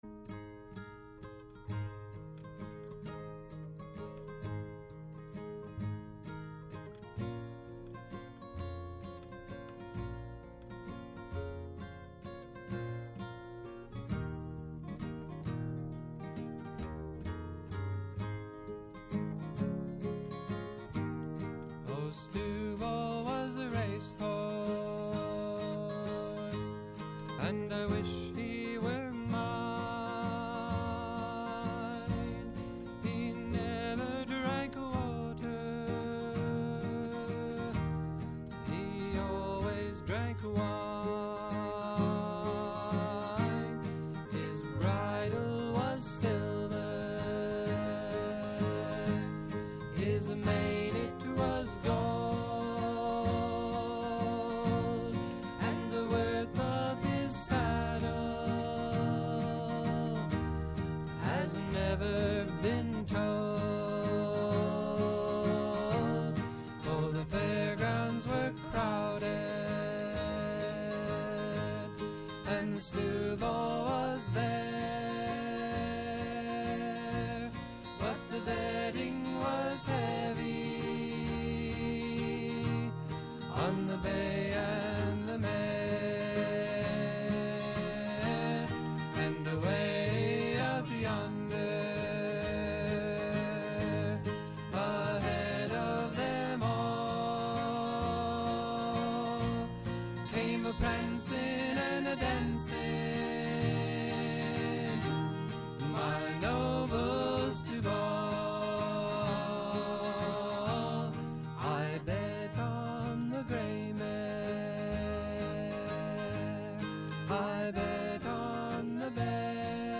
Η μακροβιότερη εκπομπή στο Ελληνικό Ραδιόφωνο!
Από το 1975 ως τον Ιούνιο 2013 και από το 2017 ως σήμερα, ο Γιάννης Πετρίδης βρίσκεται στις συχνότητες της Ελληνικής Ραδιοφωνίας, καθημερινά “Από τις 4 στις 5” το απόγευμα στο Πρώτο Πρόγραμμα